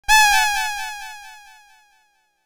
Rubber Ducky 3.wav